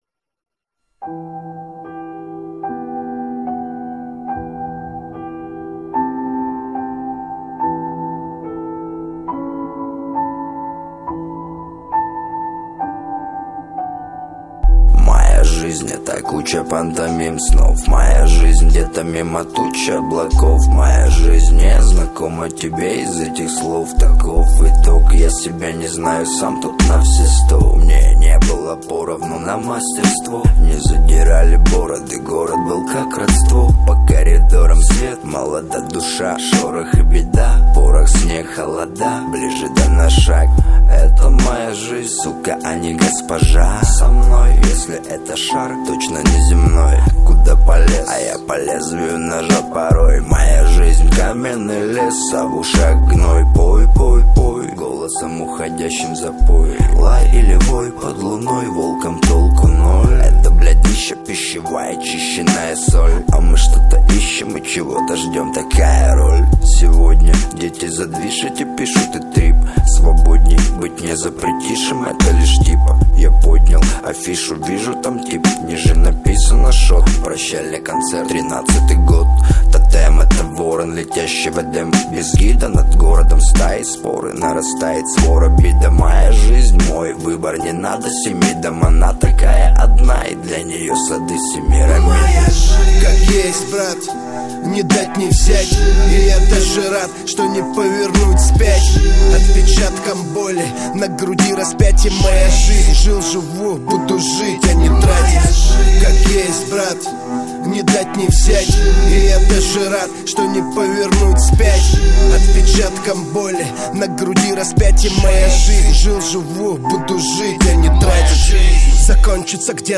Назад в (рэп)...